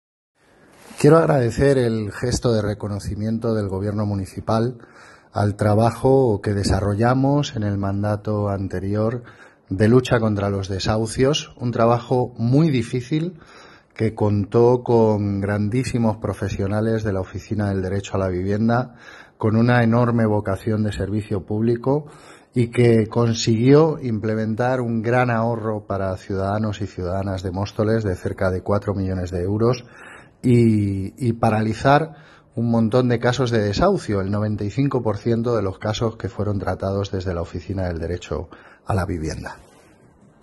Audio - Gabriel Ortega (Concejal de Cultura y Políticas Medio Ambientales) Sobre oficina antidesahucios